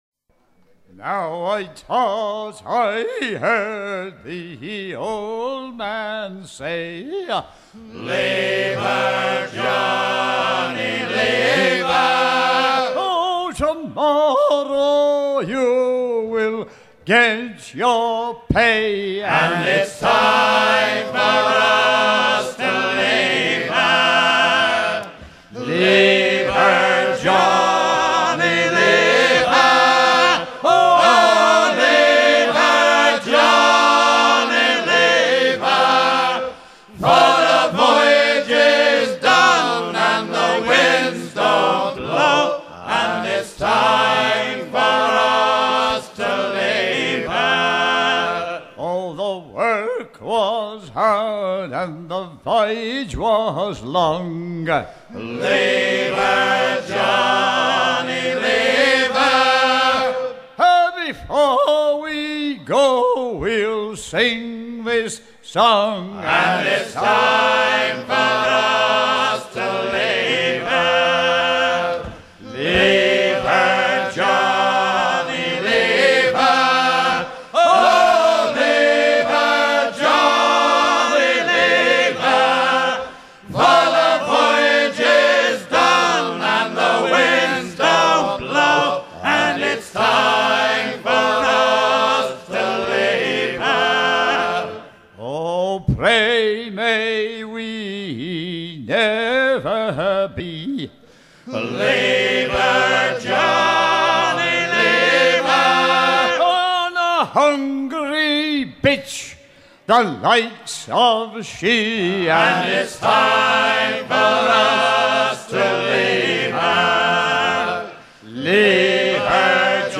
Leave her Johnny leave her Détails de l'archive Titre Leave her Johnny leave her Origine du titre : Editeur Note chant pour le dernier coup de pompe pour assécher le navire de retour de campagne.
Pièce musicale éditée